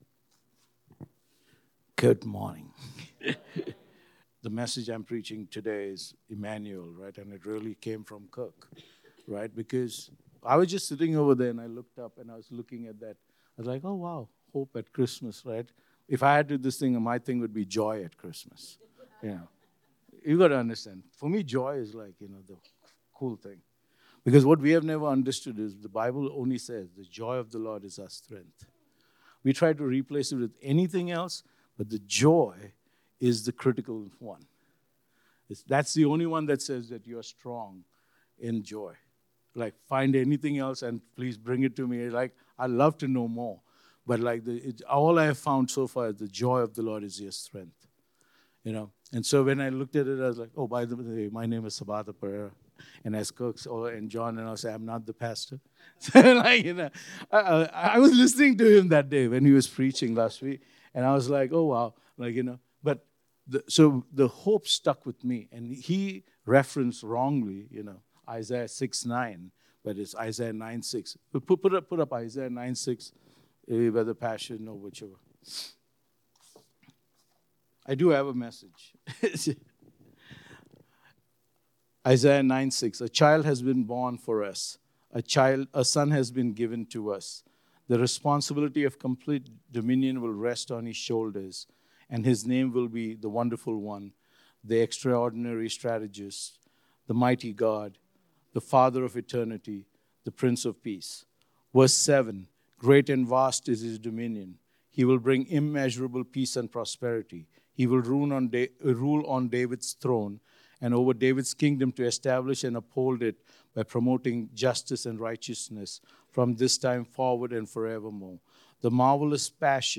Immanuel – God is With Me – SERMONS